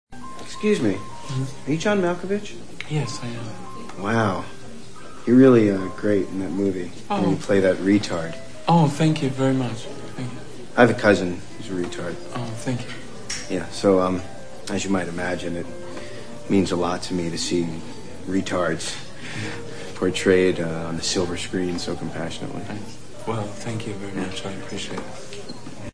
Listen to a fan of John Malkovich.